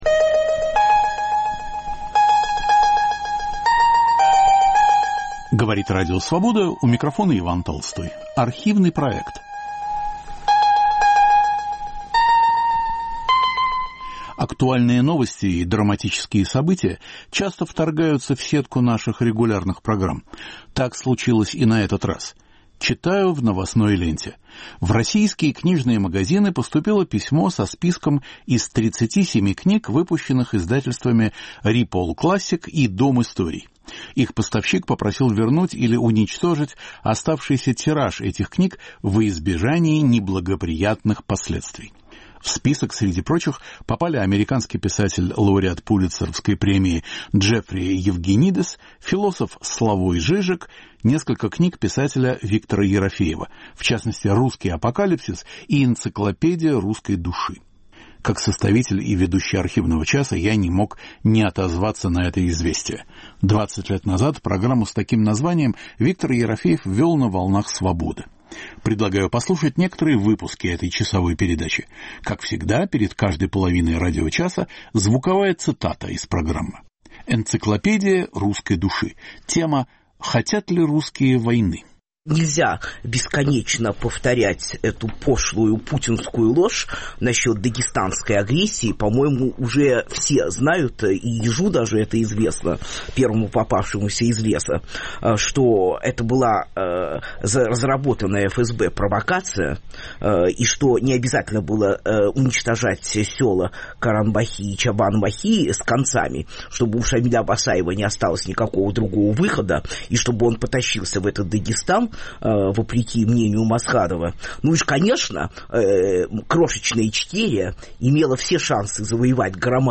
В гостях у Виктора Ерофеева Валерия Новодворская и Евгений Киселев.